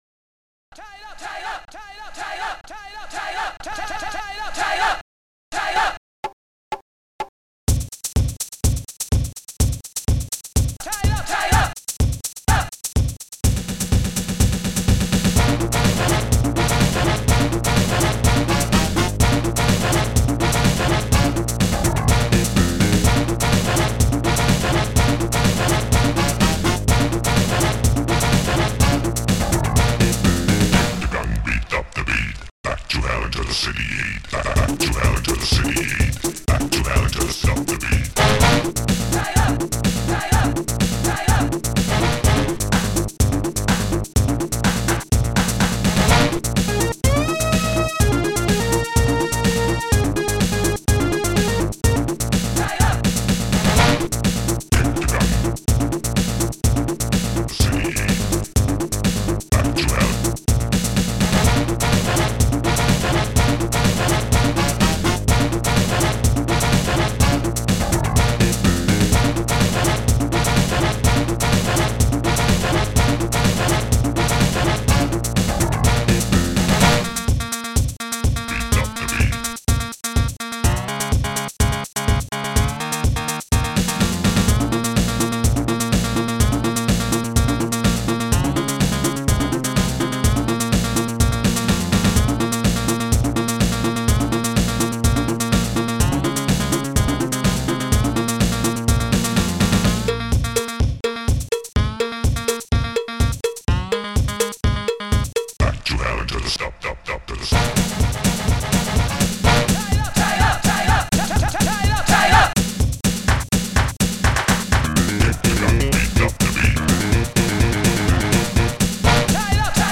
st-01:bassdrum3
st-11:saxes
st-01:shaker
st-11:deepvoice1
st-11:moog1
st-11:akaisnare
st-11:bongo
st-11:flickbass
st-11:CZSTRING
st-01:steinway
st-11:cowbell1
st-01:claps1